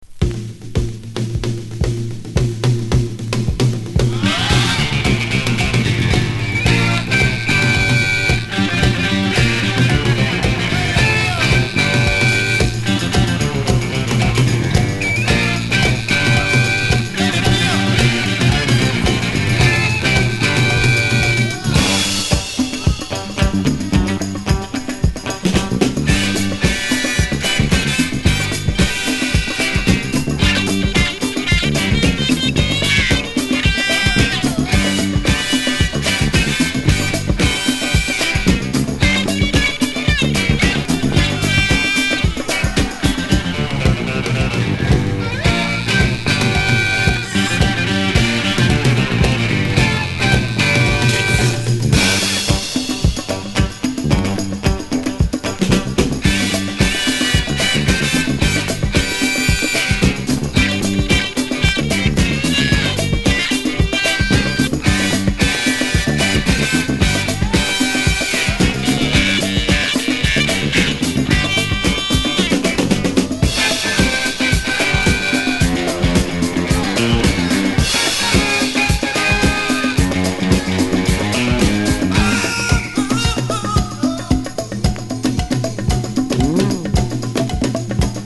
所によりノイズありますが、リスニング用としては問題く、中古盤として標準的なコンディション。